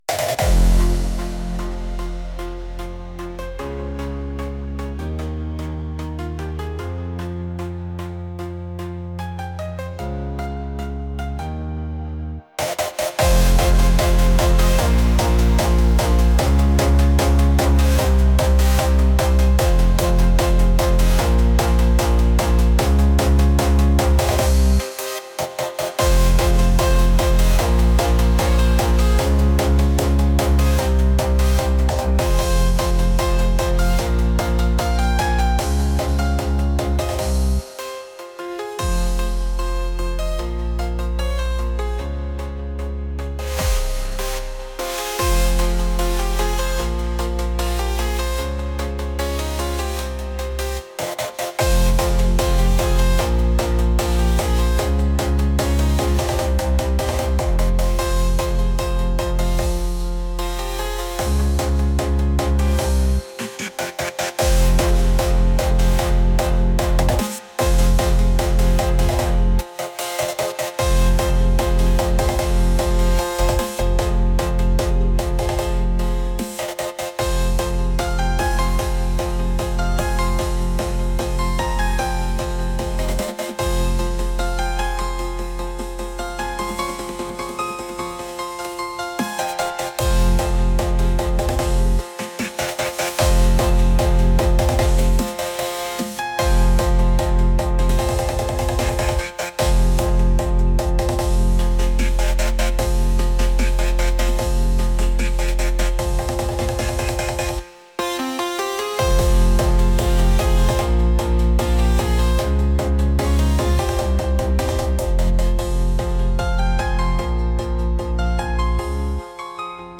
intense | energetic